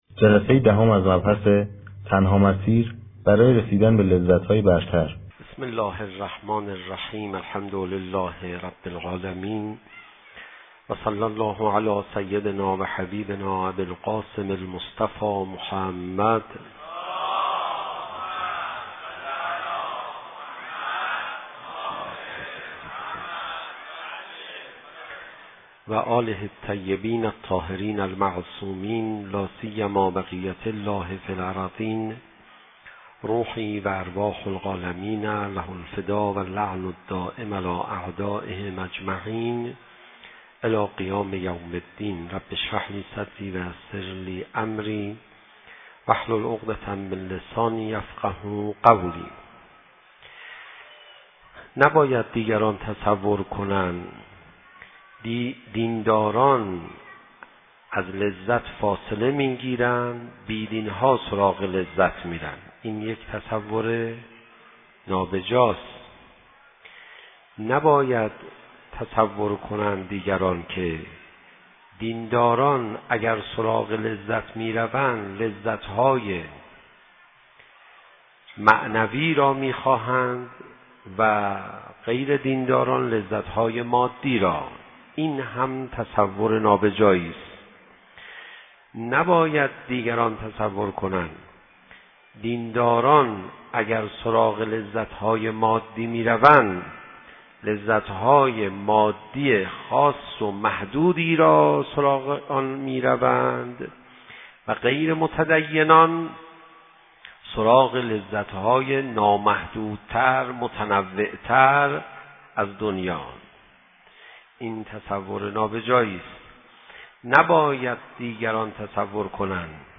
شب عاشورا محرم 97 - فاطمیه بزرگ تهران - لذتهای برتر